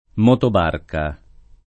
motobarca [ m q tob # rka ]